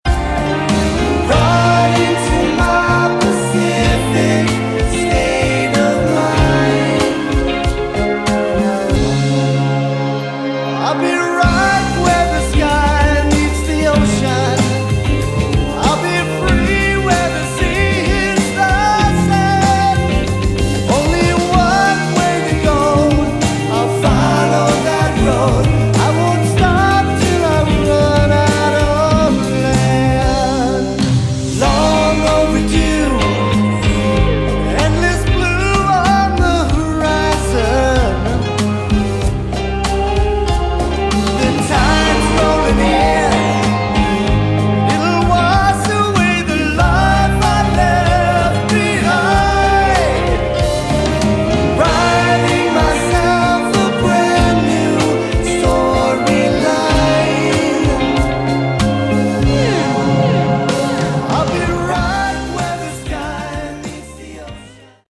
Category: Melodic Rock
vocals
guitar, bass, keyboards
drums